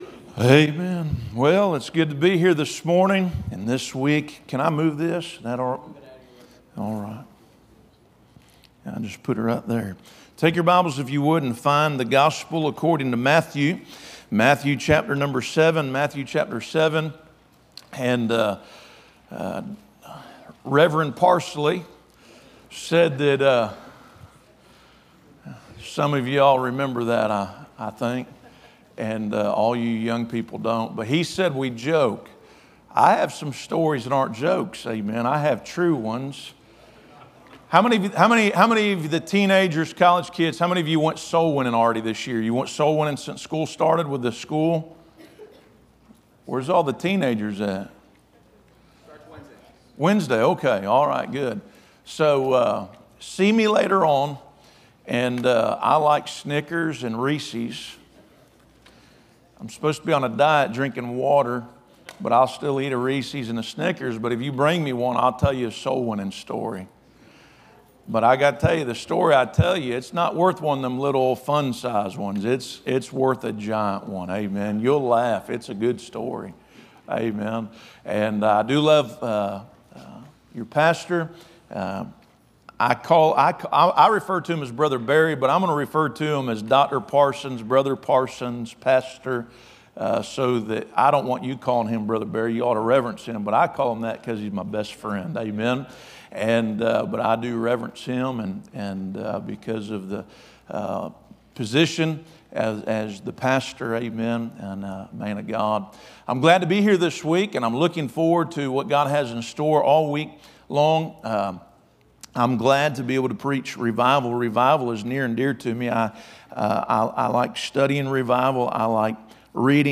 Series: Back to School Revival
Preacher